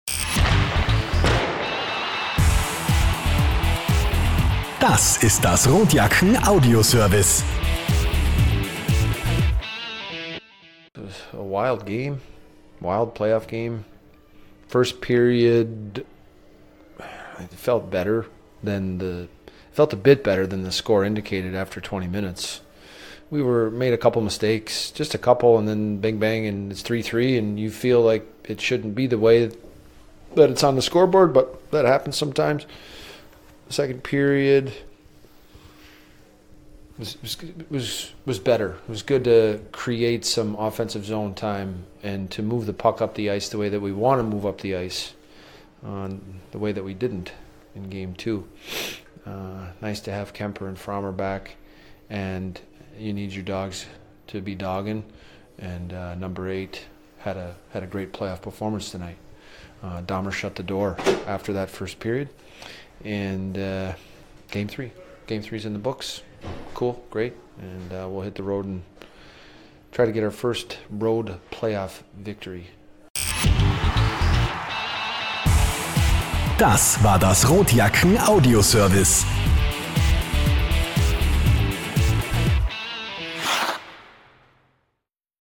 Post Game